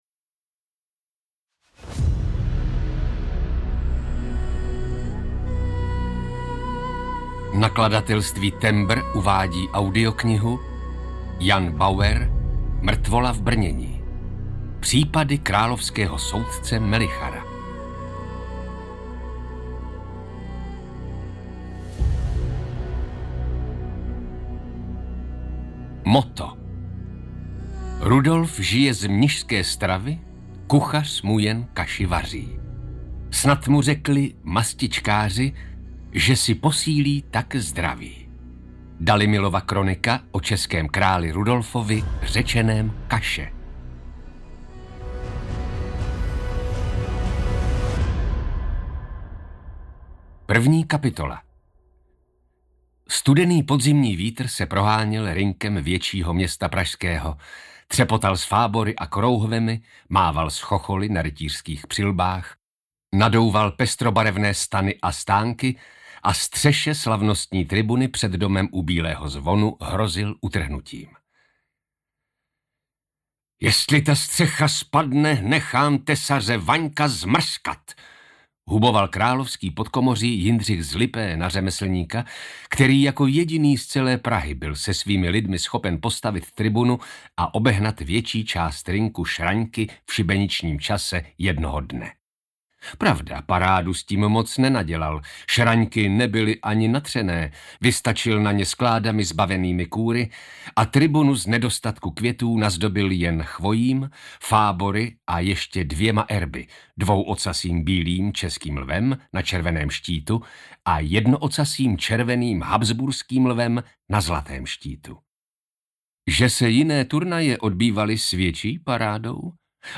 Mrtvola v brnění audiokniha
Ukázka z knihy
mrtvola-v-brneni-audiokniha